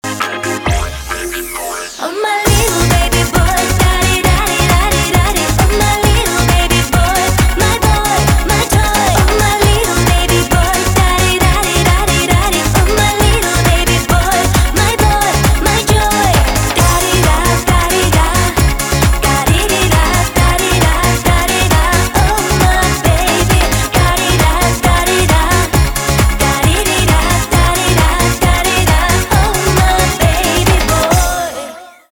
поп
битовые